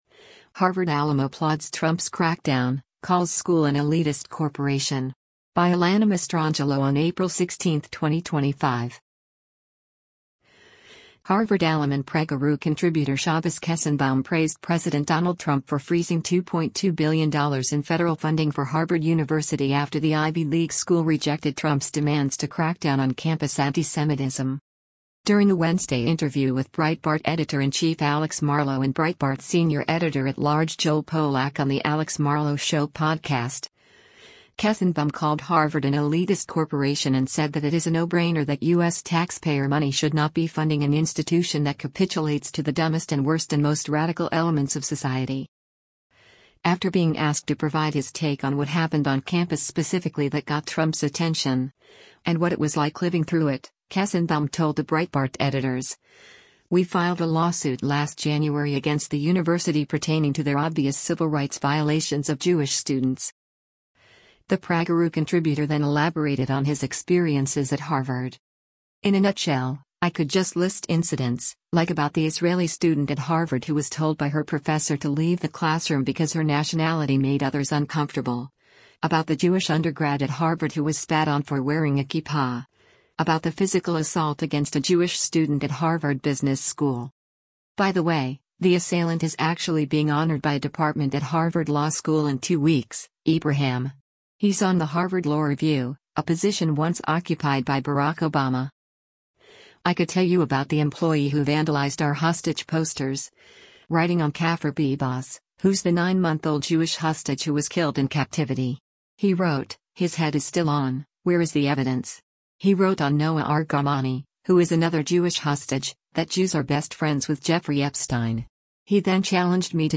During a Wednesday interview